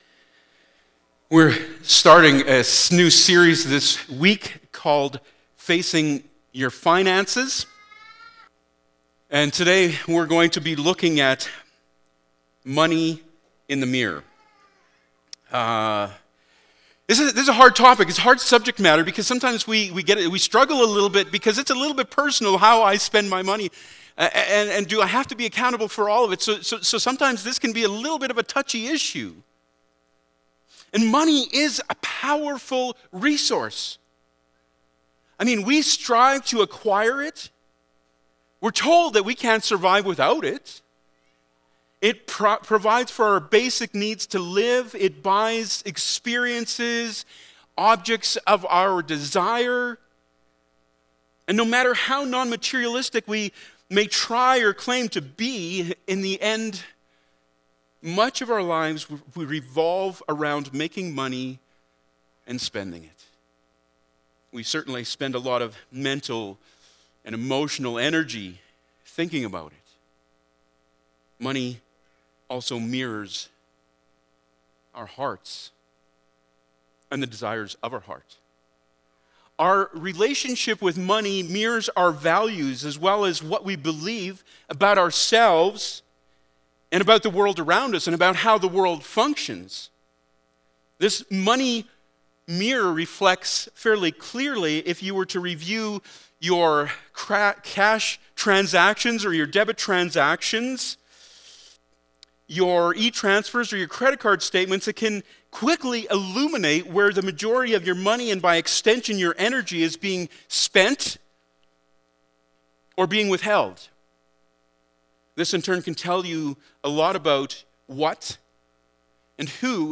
Passage: Matthew 6:19-24 Service Type: Sunday Morning